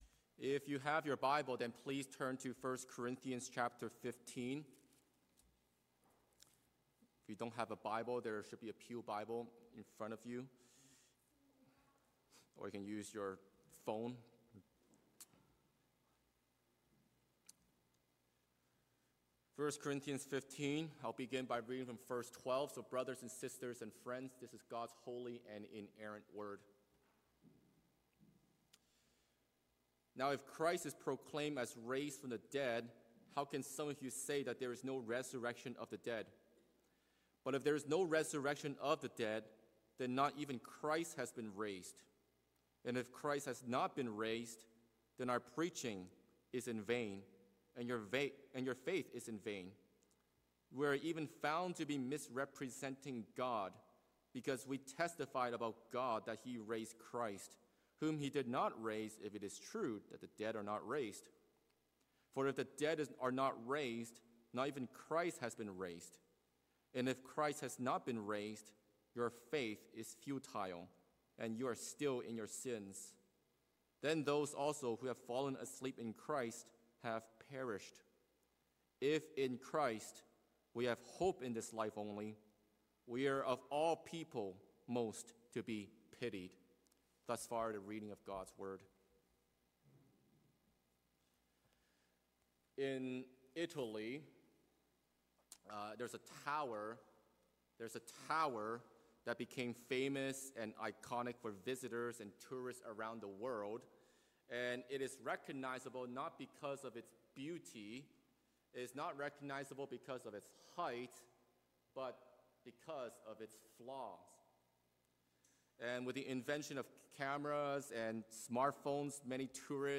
Download Download Reference 1 Corinthians 15:12-19 From this series Current Sermon What Would Make Christianity Collapse?